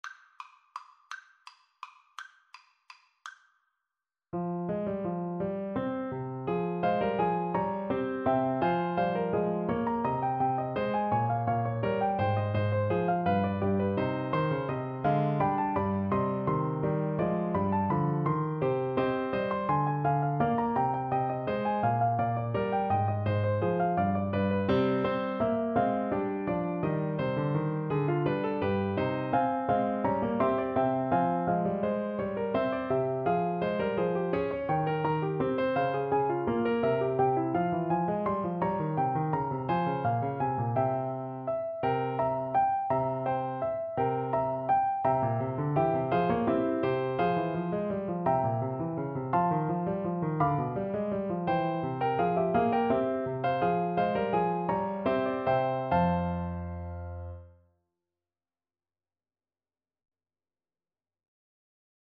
3/8 (View more 3/8 Music)
Classical (View more Classical Contralto Voice Music)